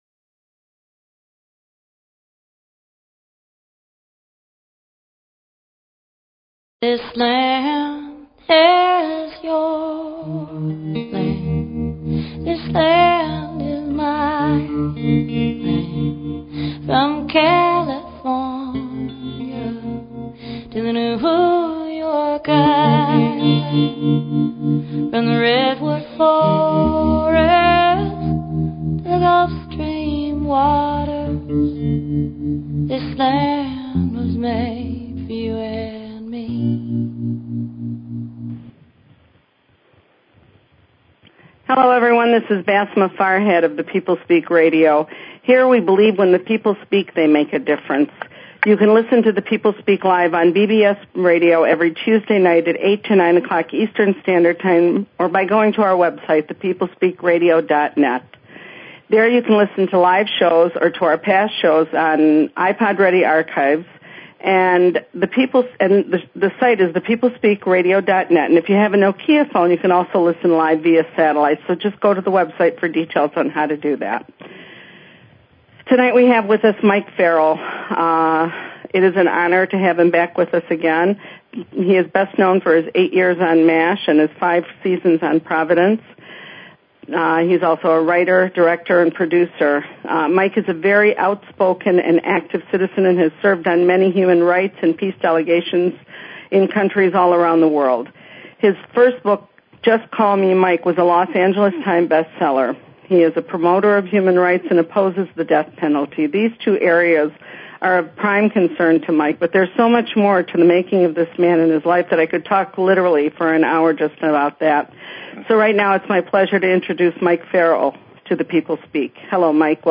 Talk Show Episode, Audio Podcast, The_People_Speak and Mike Farrell on , show guests , about , categorized as Entertainment,Politics & Government
Guest, Mike Farrell